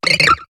Cri de Baggiguane dans Pokémon HOME.